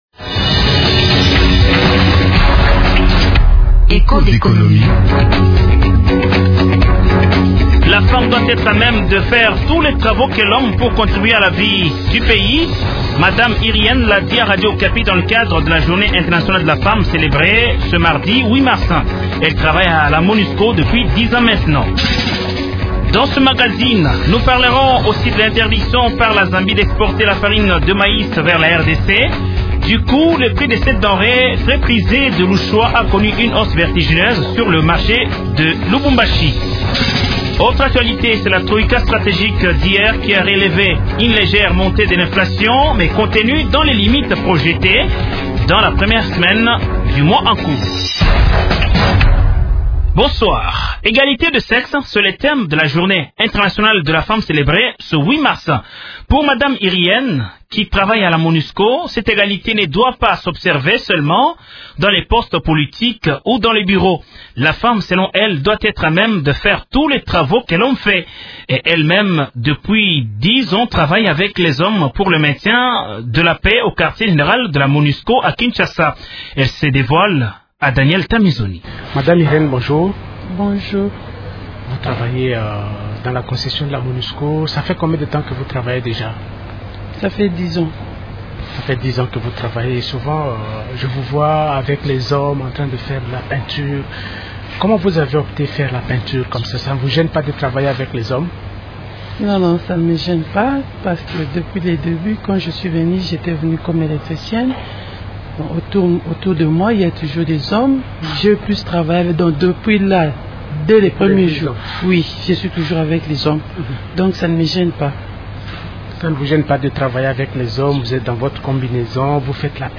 Ce magazine a fait parler deux femmes